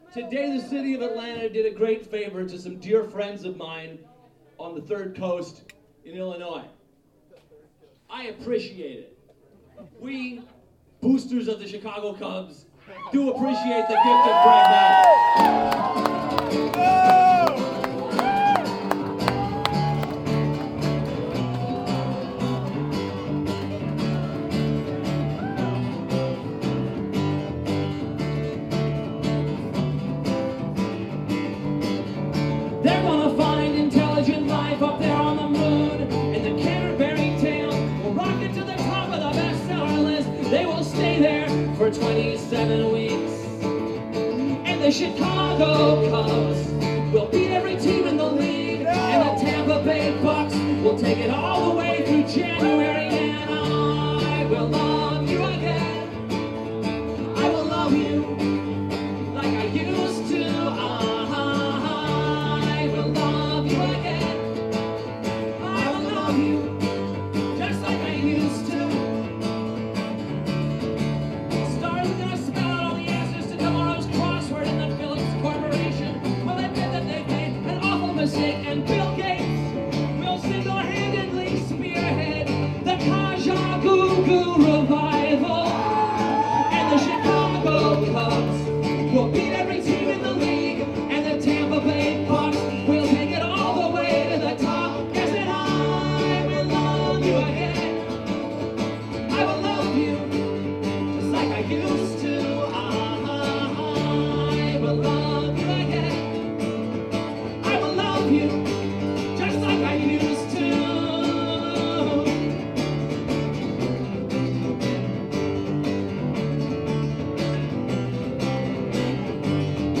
Today we have a live recording of